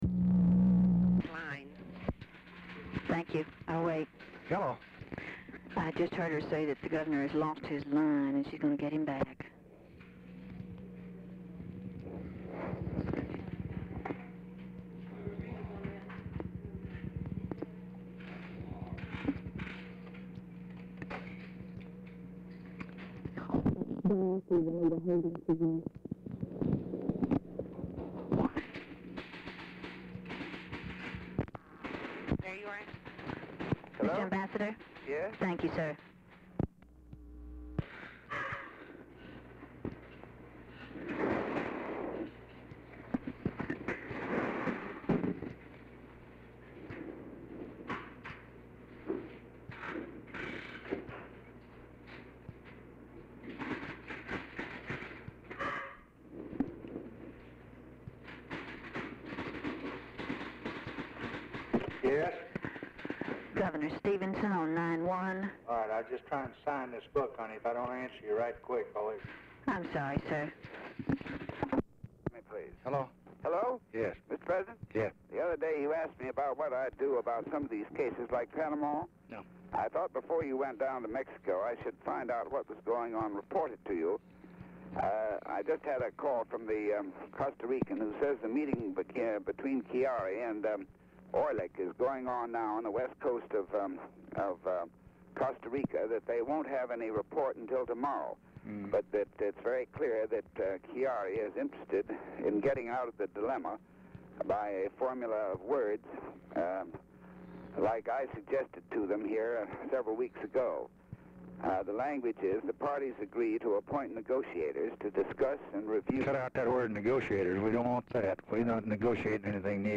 Telephone conversation # 2147, sound recording, LBJ and ADLAI STEVENSON, 2/20/1964, 5:00PM | Discover LBJ
STEVENSON ON HOLD ABOUT 1:00 WAITING FOR RECONNECTION
Format Dictation belt
Specific Item Type Telephone conversation